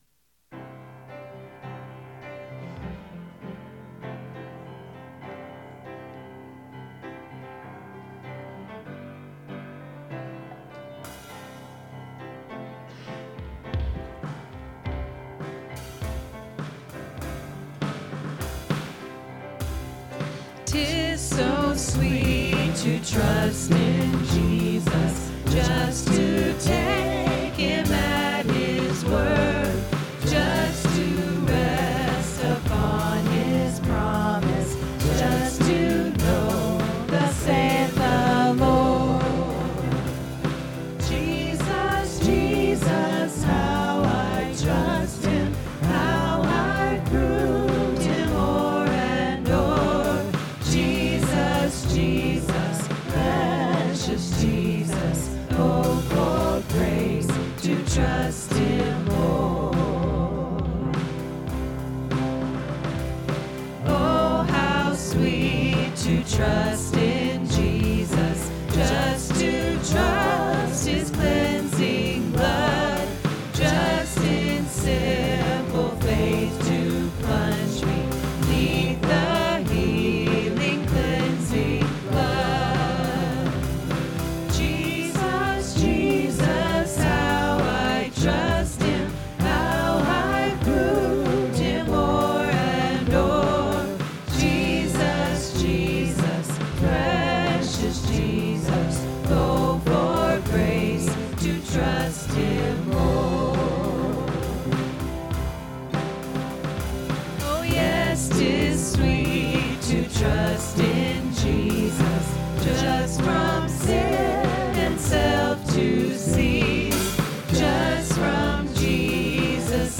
5th Sunday Sing